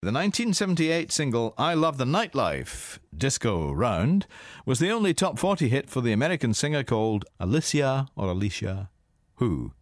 Here's Ken with today's tough PopMaster question.